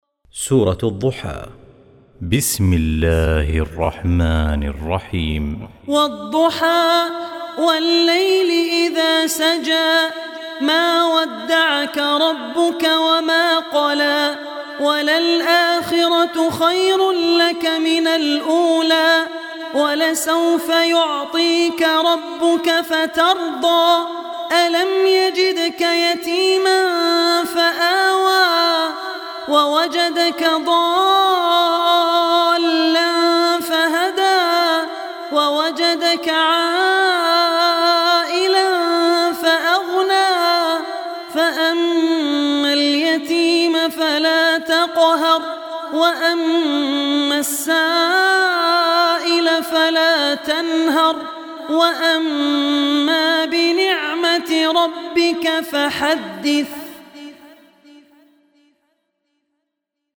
Surah Ad-Duha Recitation by Abdur Rehman Al Ossi
93-surah-duha.mp3